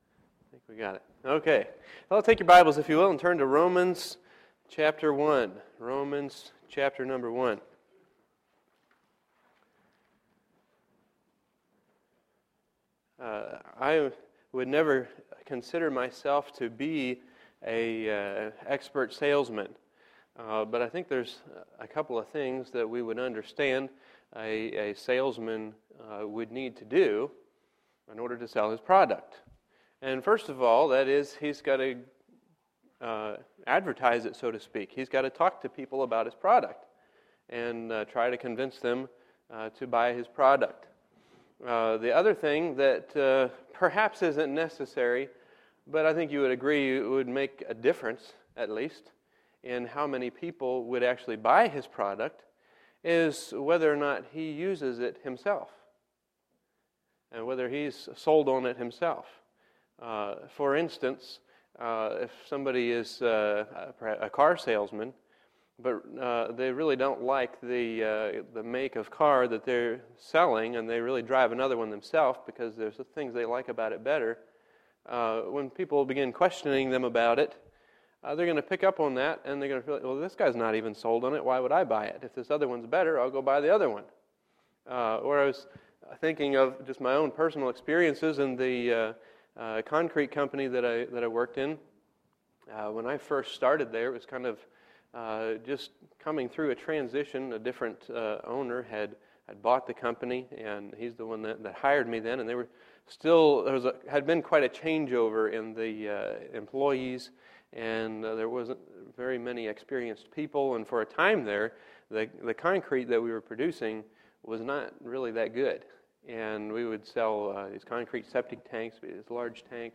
Wednesday, September 26, 2012 – Wednesday PM Session